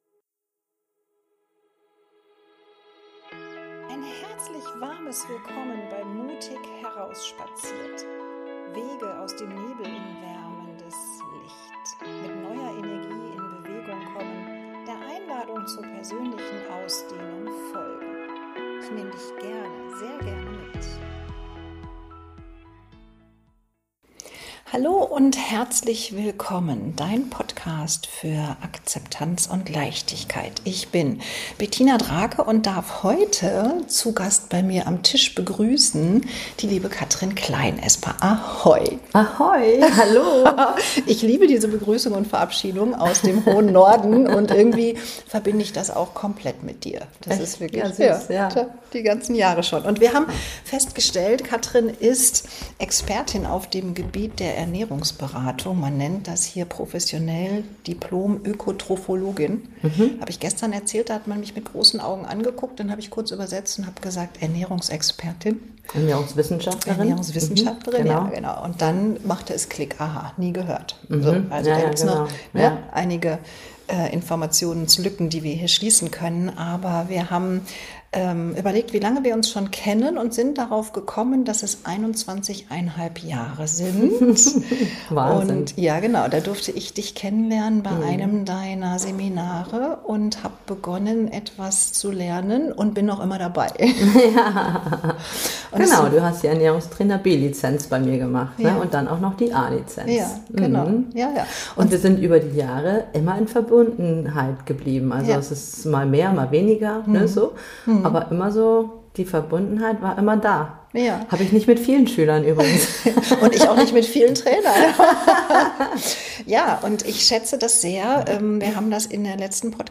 Wir hatten viel Freude beim Interview und wünschen euch ebensoviele Freude beim Zuhören und nachmachen!